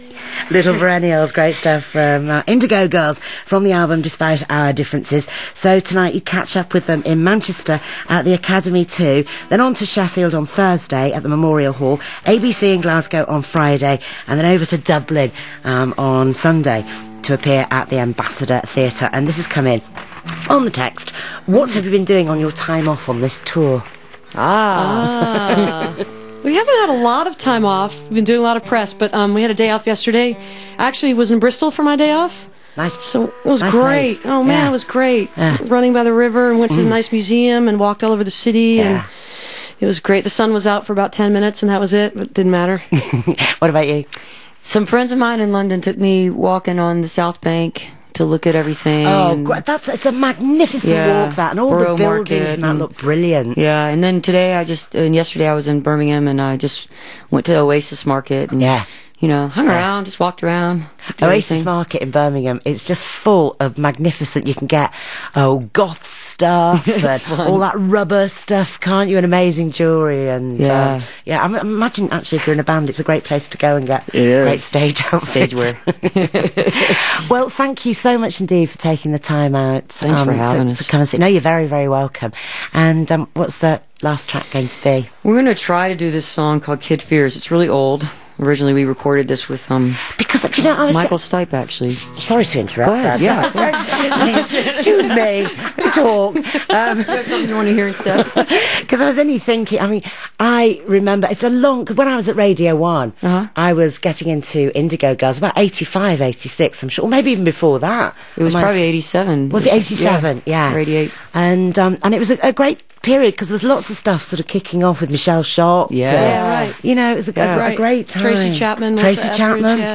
05. interview (2:41)